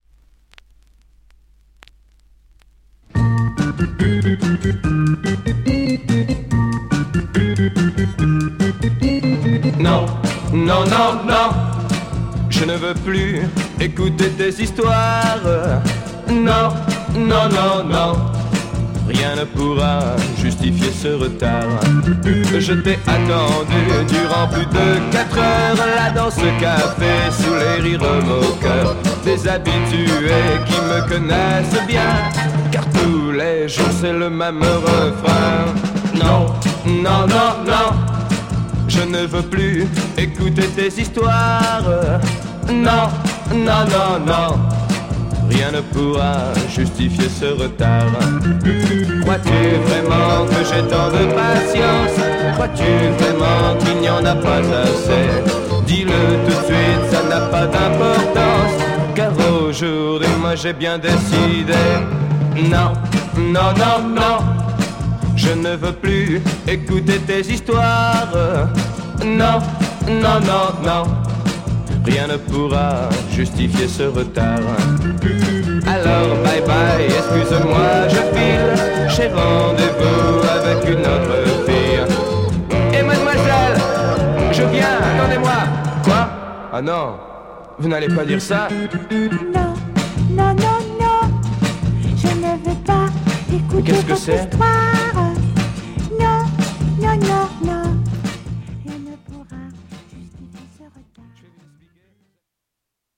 French Popcorn Yéyé